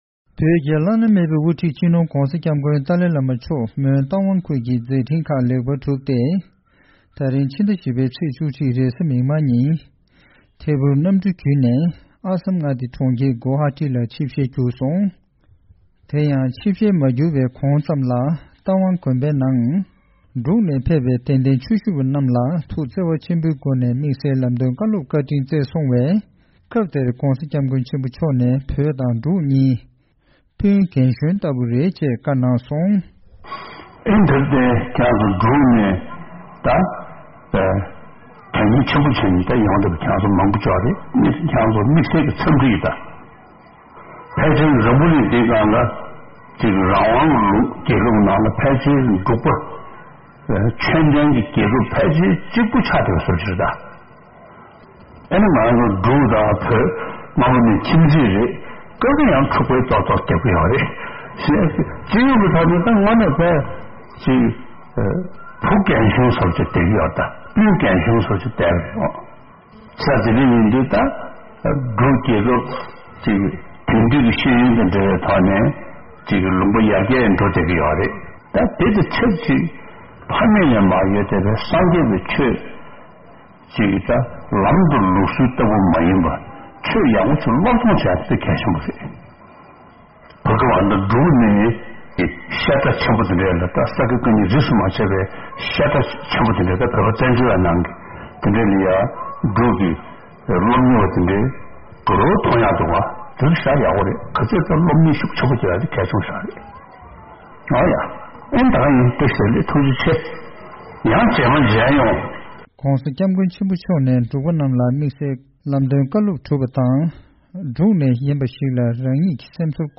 Before taking chopper flight to Guwahati in Assam, the Dalai Lama addressed his Bhutanese devotees present at the 3-day teaching in Tawang, Arunachal Pradesh, and said, "Tibet and Bhutan are like elder and younger brother of a family" and advised them to put more effort in studying Buddhist texts alongside modern education.